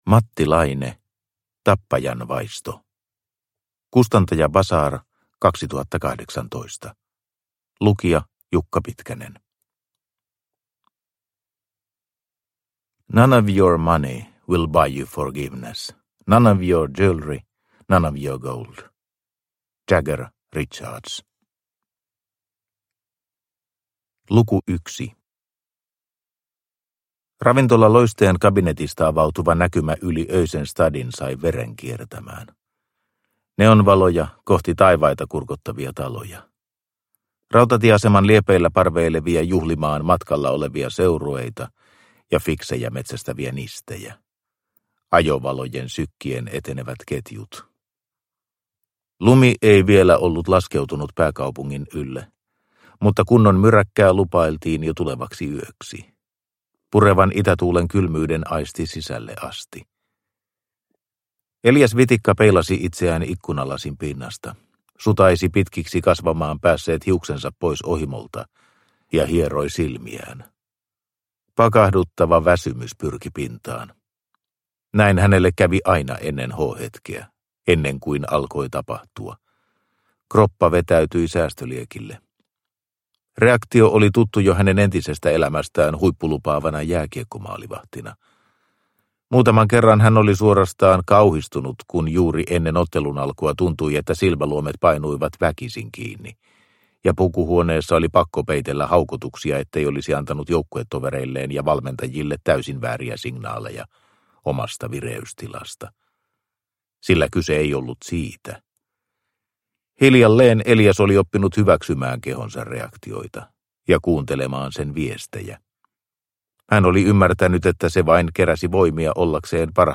Tappajan vaisto – Ljudbok – Laddas ner